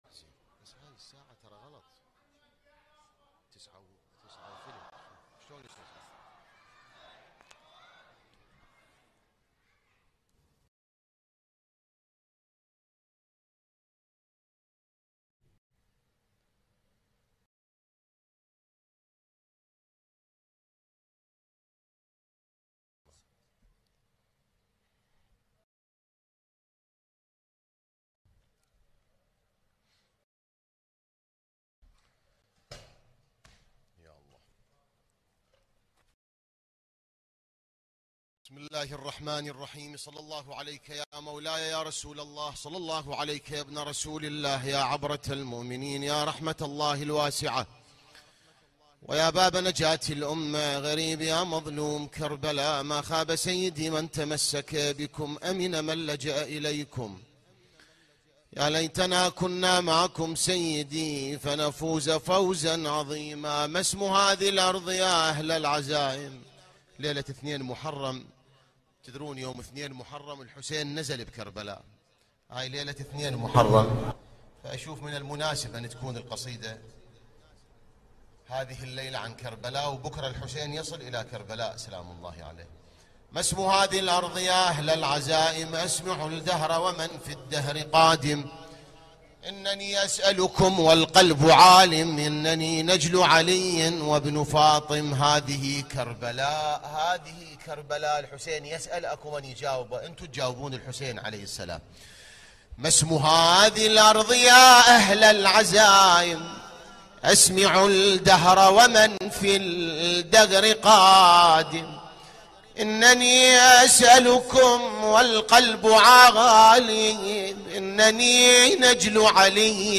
الان-مباشرة-ليلة-٢-محرم-١٤٤٦هـ-هيئة-الزهراء-للعزاء-المركزي-في-النجف-الاشرف.mp3